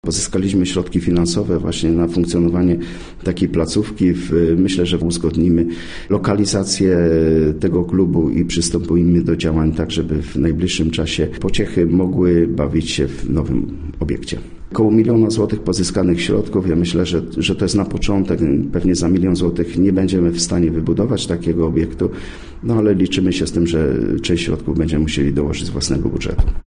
– mówił wójt, Sylwester Skrzypek.